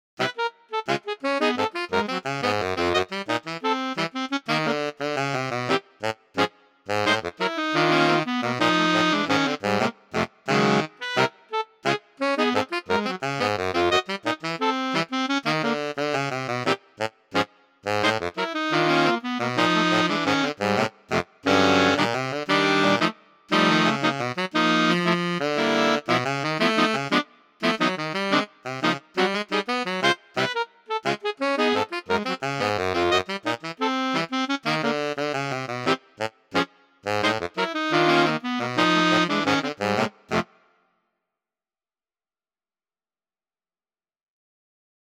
• Short Pieces for Saxophone Quartet
We managed, but the effect was very different from the precise computer-rendered versions here.